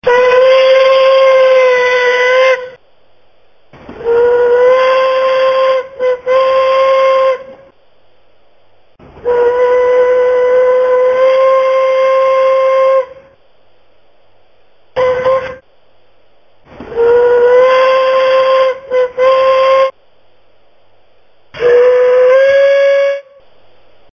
Recorded Whistles for Live Steam Locomotives
whistles_na.mp3